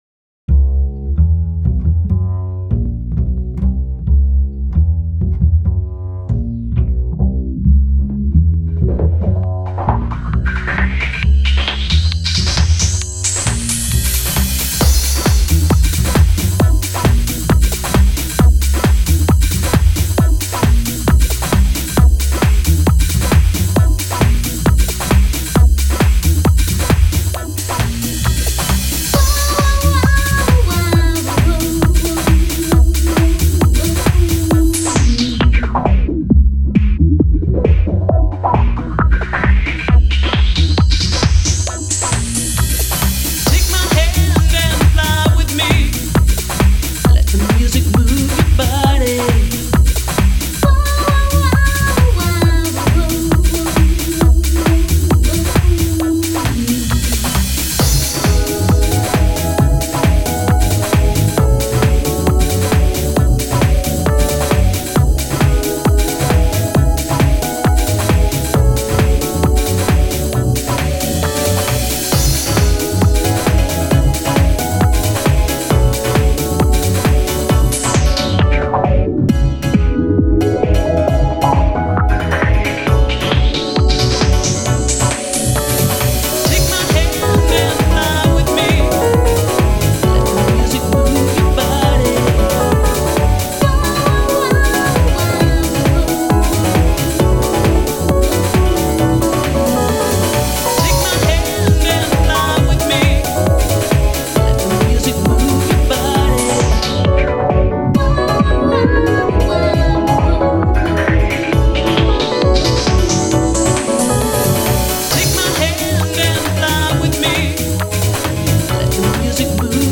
Light Filter House
HOUSEというジャンルでは一般的で軽めなフィルターとともに、好きなだけアドリブを録音し